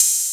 Open Hats
offset oh.wav